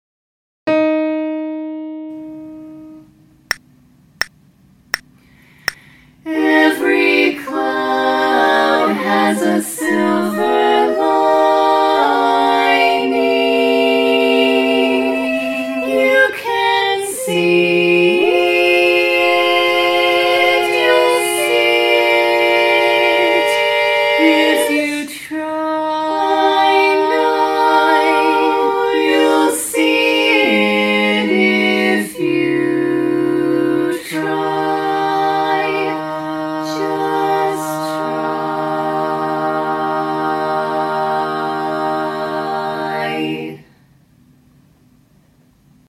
Celebrate the positive with this inspirational tag!